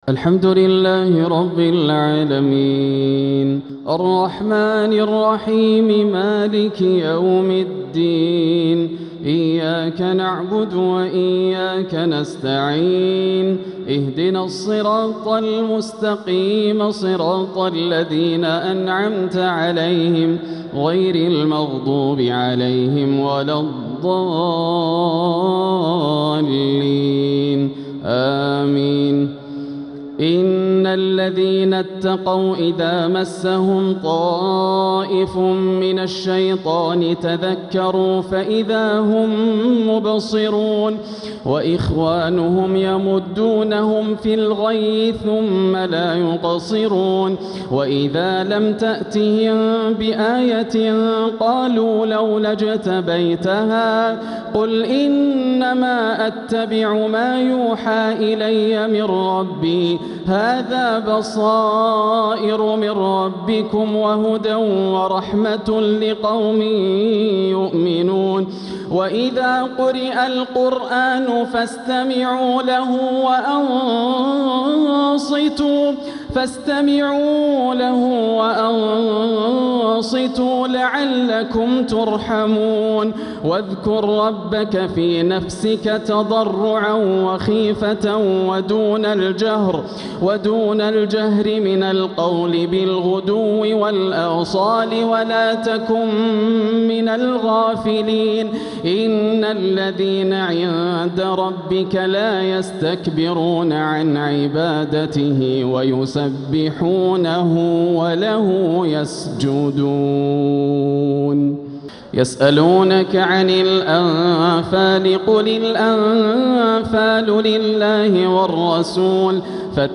تلاوة ملئت عذوبة من سورتي الأعراف والأنفال | تراويح ليلة 12 رمضان 1447 > الليالي الكاملة > رمضان 1447 هـ > التراويح - تلاوات ياسر الدوسري